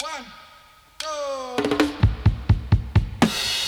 131-FILL-FX.wav